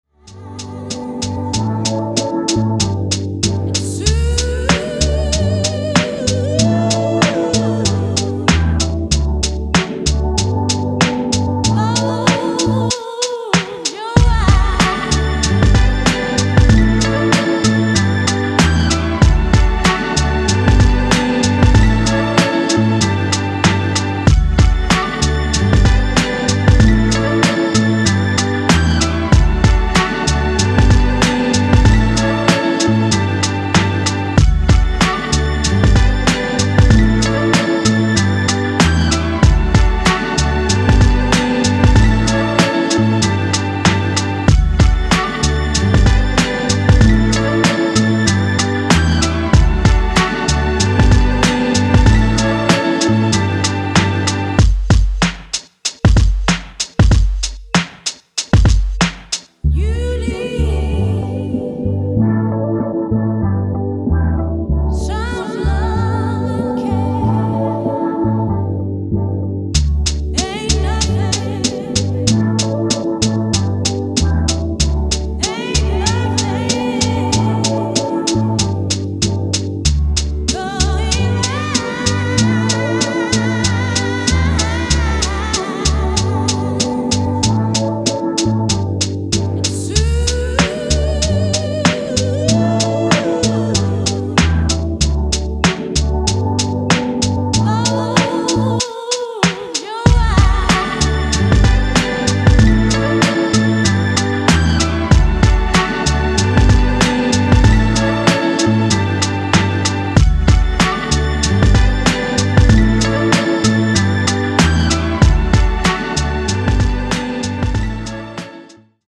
Techno Acid Rave